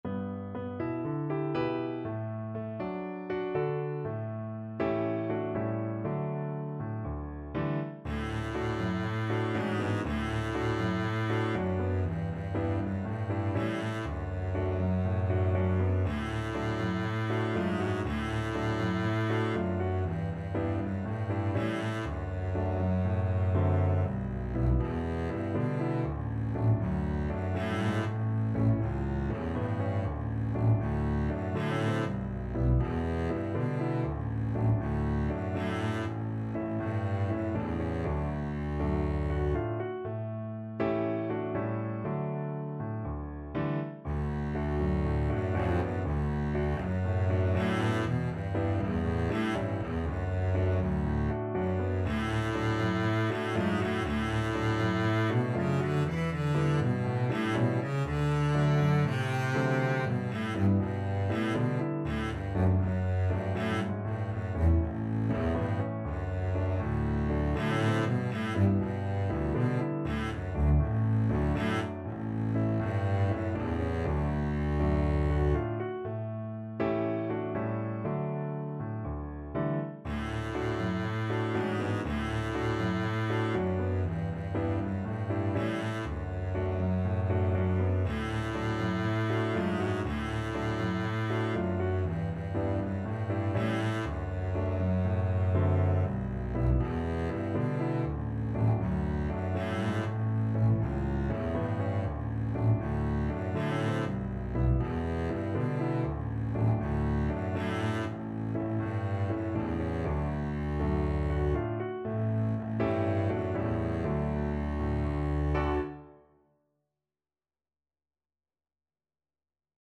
4/4 (View more 4/4 Music)
Calypso = 120